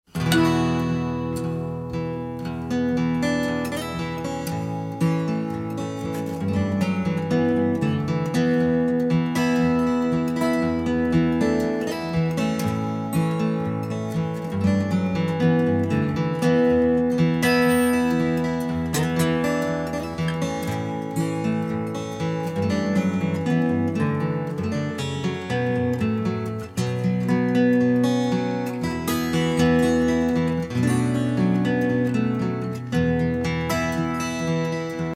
at Sweet Silence Studios, Denmark,
fiddle & bouzouki